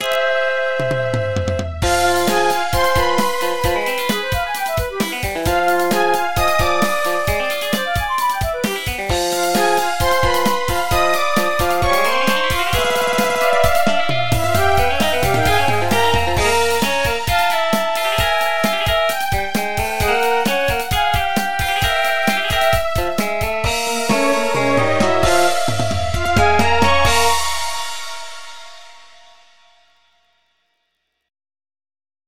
MIDI 4.16 KB MP3 (Converted) 0.49 MB MIDI-XML Sheet Music